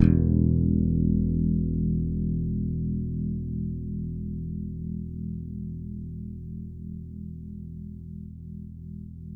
WARW.FING E1.wav